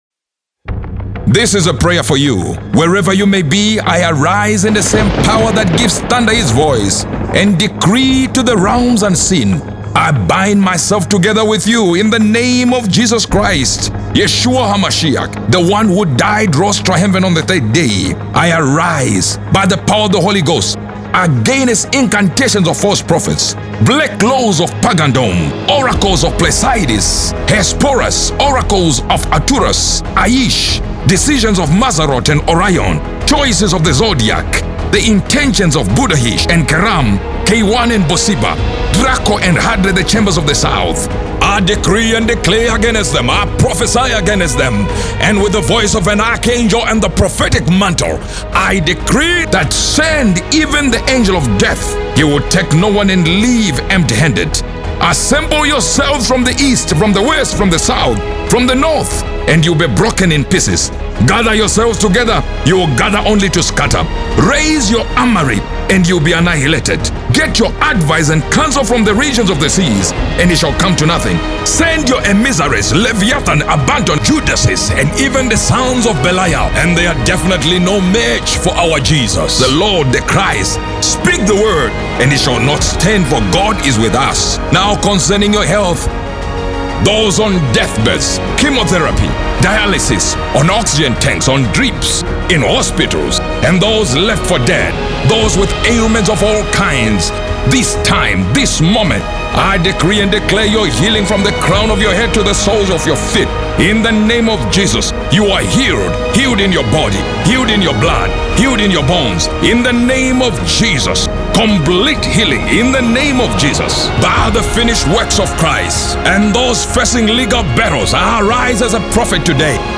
A-Prophetic-Prayer-for-You-2025.mp3